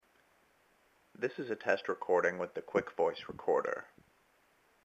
So to get decent volume I had to run the 1/8 inch cable from my iPhone through my M-Audio MobilePre USB audio interface.
In each case, I pointed the microphone (on the bottom of the iPhone) toward me and held it about six inches away. I didn't run any compression, signal gain or otherwise mess with the recordings other than to crop them.
quickvoice-recorder-sample.mp3